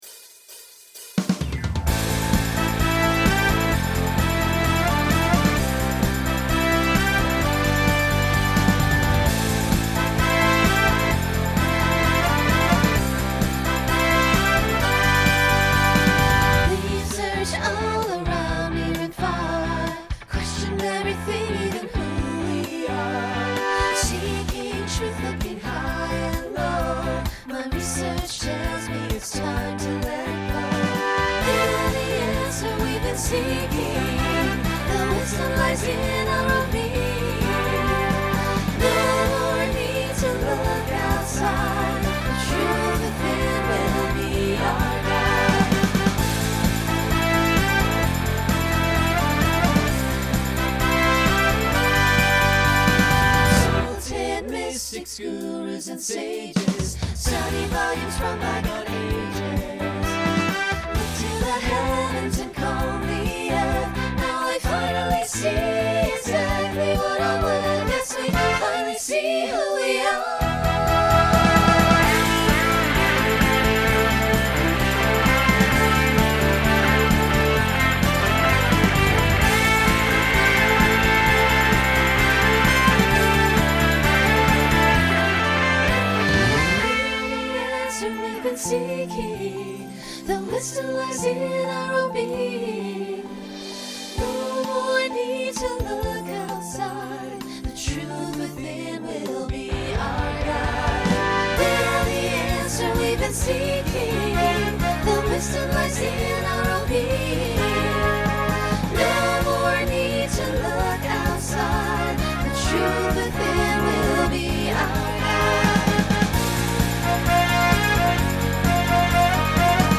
Pop/Dance , Rock Instrumental combo
Original Song Show Function Closer Voicing SAB